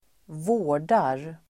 Uttal: [²v'å:r_dar]